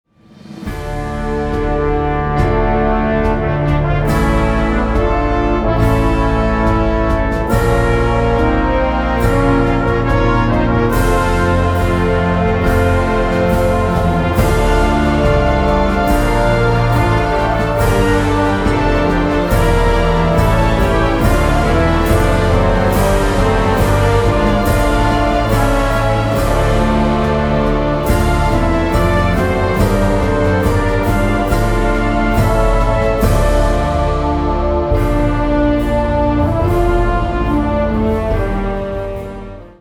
без слов
инструментальные
тревожные
оркестр